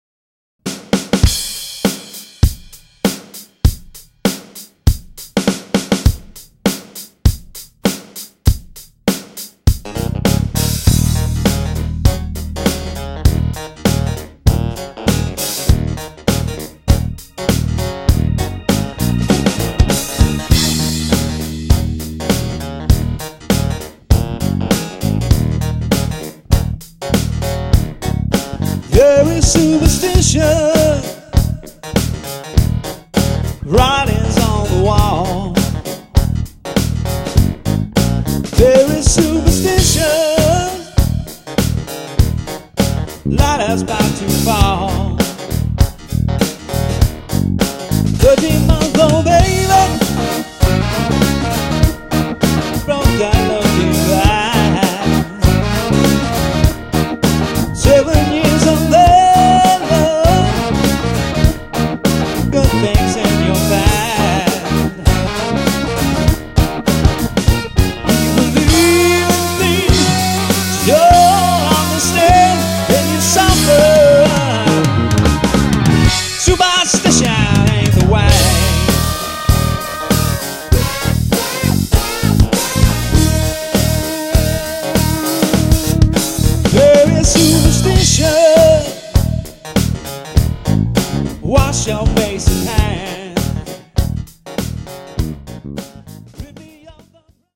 corporate cover band project